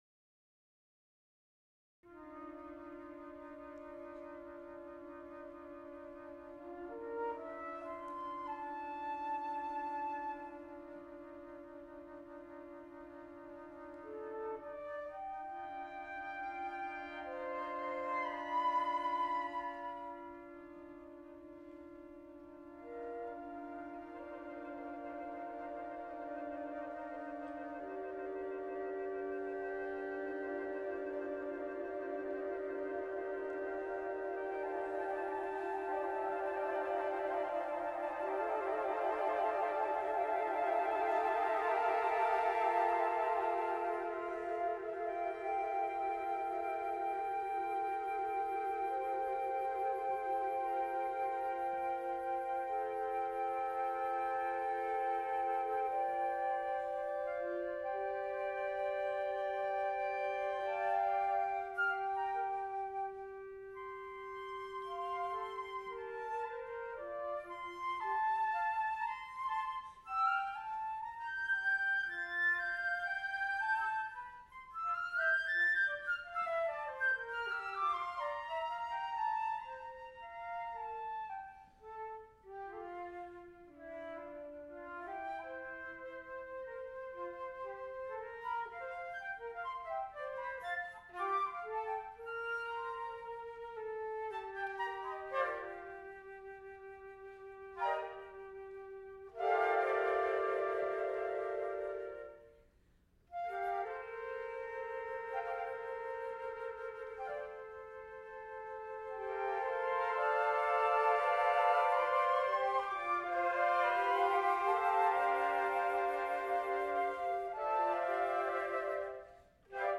Flute choir